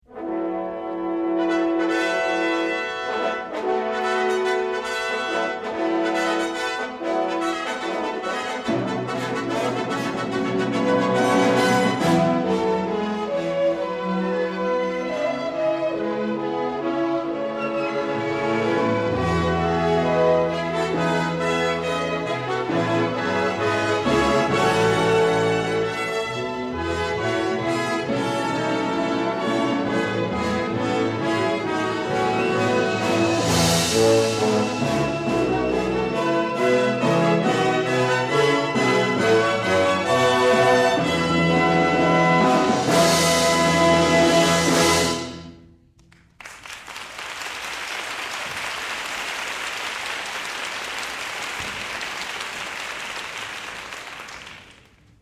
for Orchestra